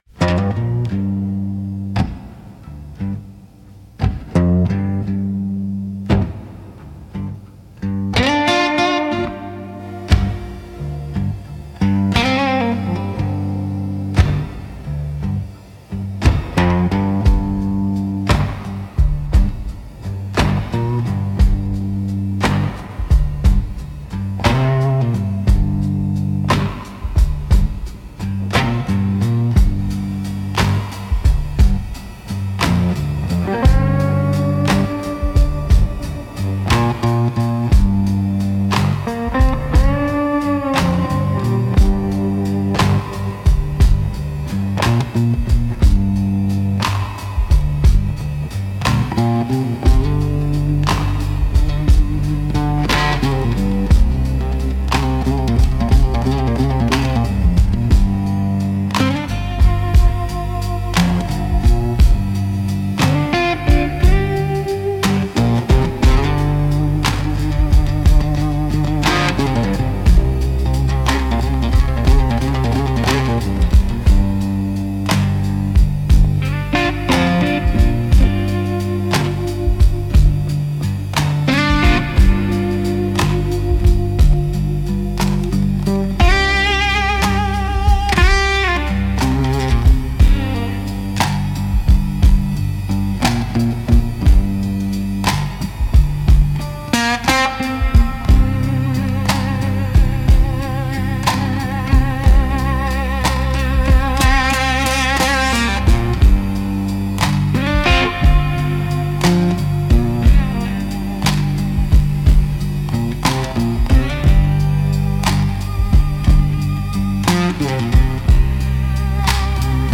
Instrumental - Slow Burn Through the Pines